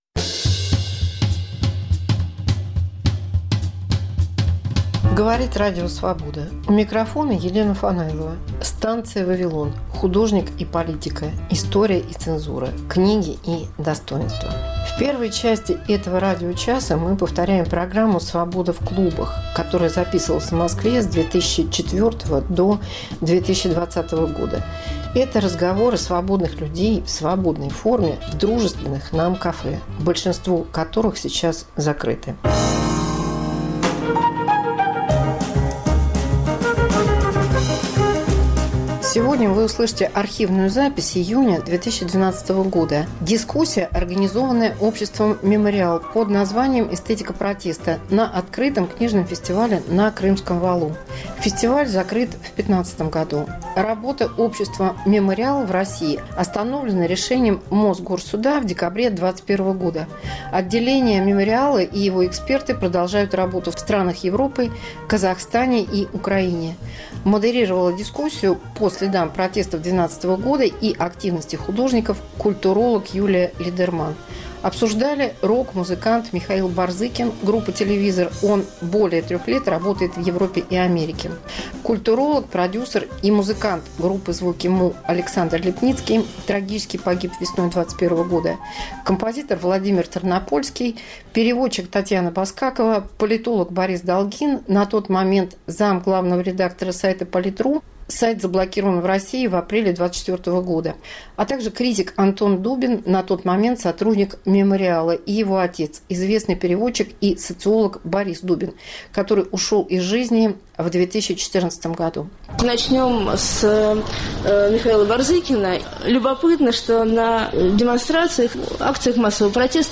Мегаполис Москва как Радио Вавилон: современный звук, неожиданные сюжеты, разные голоса